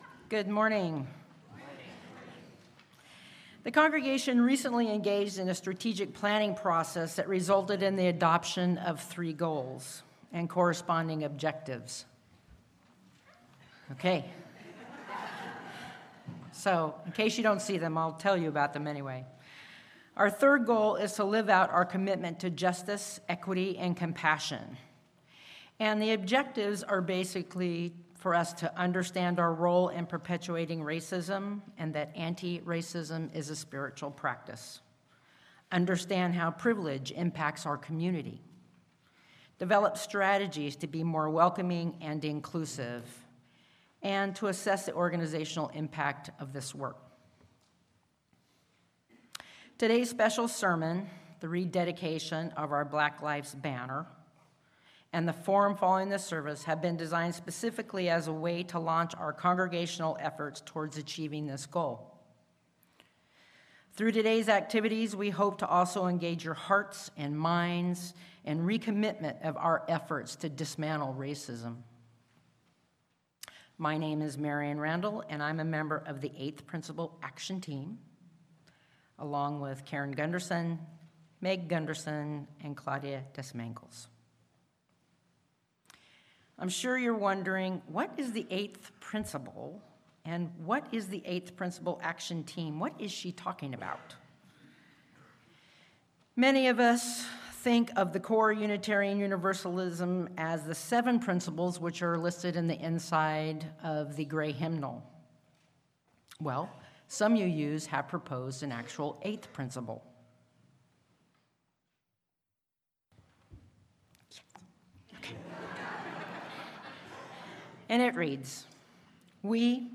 Audio timing notes: 0:00 Introduction of 8th Principle Action Team 8:06 Reflection 12:52 Sermon 1 24:06 Sermon 2
Sermon-a-Journey-toward-Wholeness.mp3